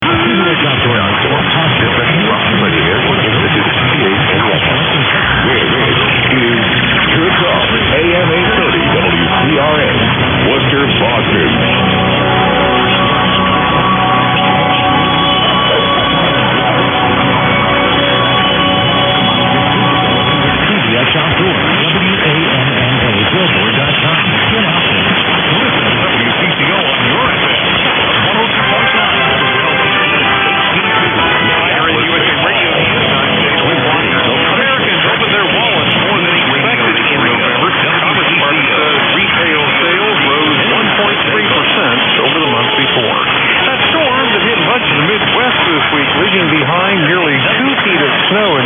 091212_0900_830_wcrn_wcco_confusing_ad.mp3